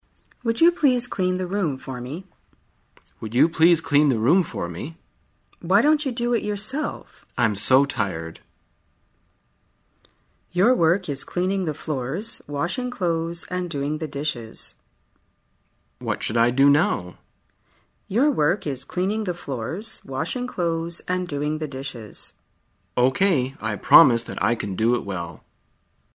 在线英语听力室生活口语天天说 第2期:怎样布置做家务的听力文件下载,《生活口语天天说》栏目将日常生活中最常用到的口语句型进行收集和重点讲解。真人发音配字幕帮助英语爱好者们练习听力并进行口语跟读。